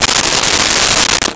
Metal Zipper 04
Metal Zipper 04.wav